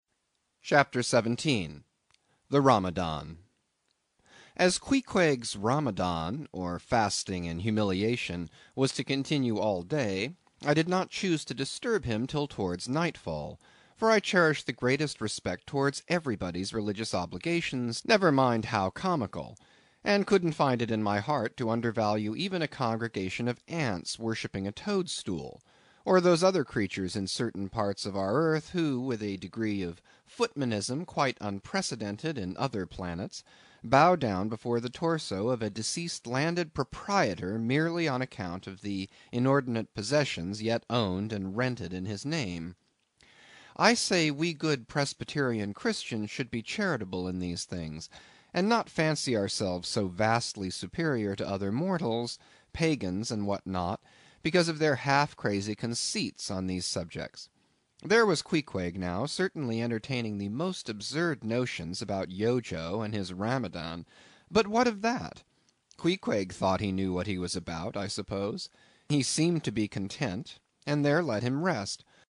英语听书《白鲸记》第83期 听力文件下载—在线英语听力室